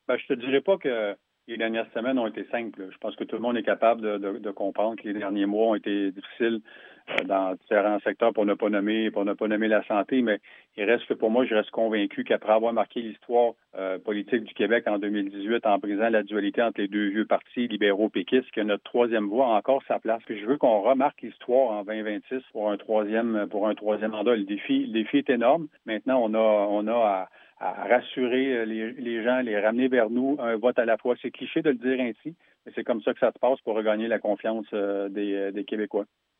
François Bonnardel, député de Granby, fait un retour sur sa décision de ne pas se présenter dans la course à la succession lors d’une entrevue accordée au Service de nouvelles de M105. Il qualifie cette décision de « personnelle » et se dit rassuré de l’héritage de la Coalition avenir Québec (CAQ), qu’il estime entre bonnes mains avec les deux candidats actuellement en lice.